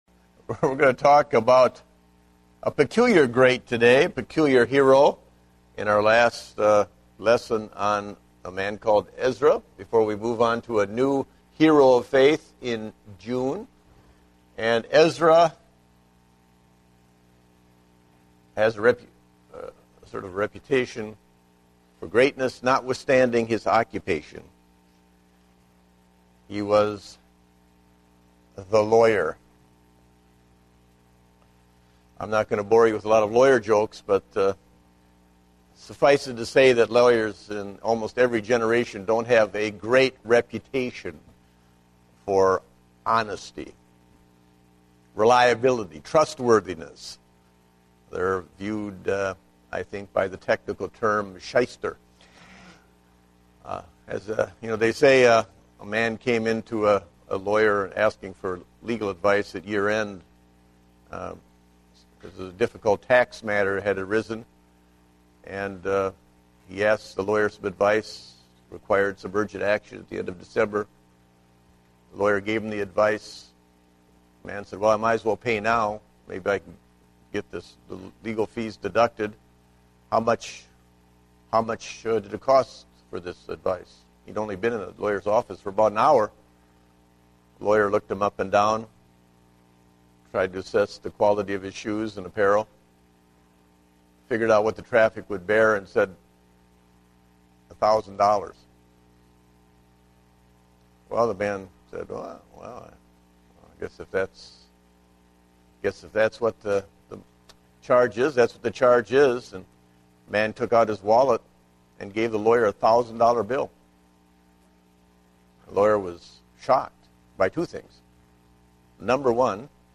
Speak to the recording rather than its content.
Date: May 30, 2010 (Adult Sunday School)